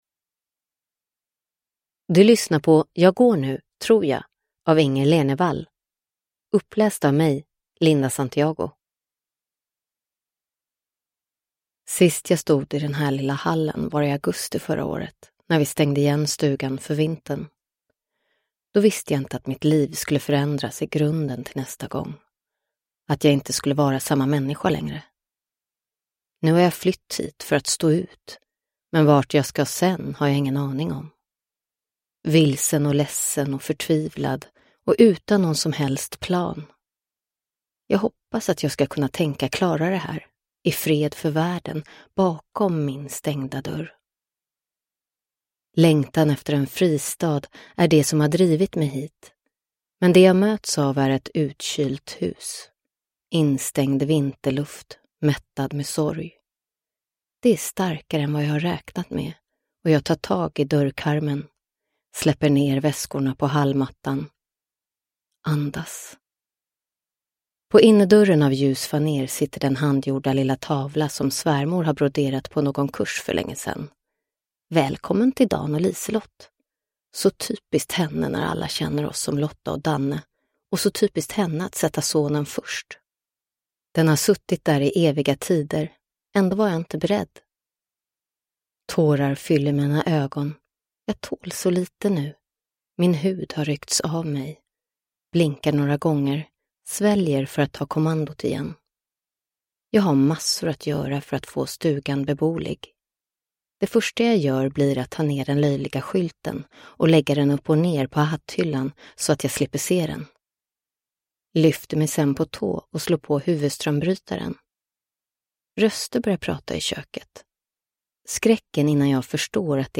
Jag går nu. Tror jag – Ljudbok – Laddas ner